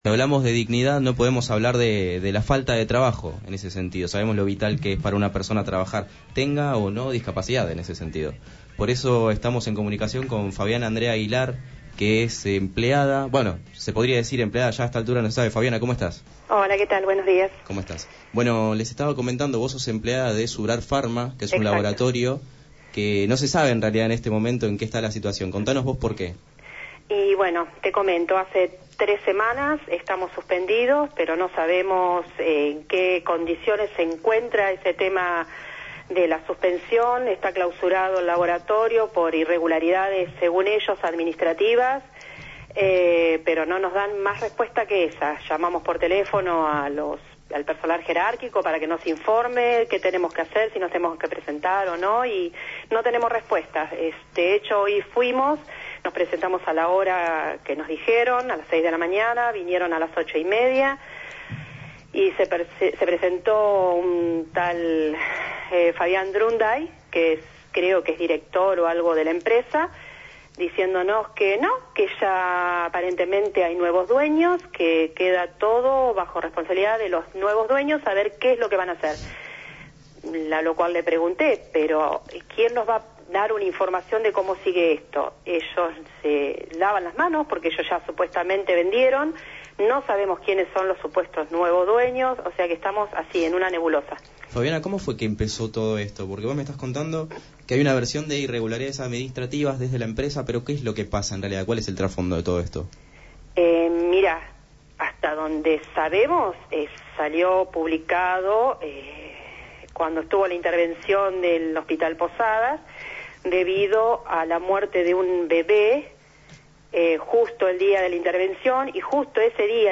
En Sin Condiciones entrevistaron